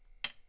hitWood1.wav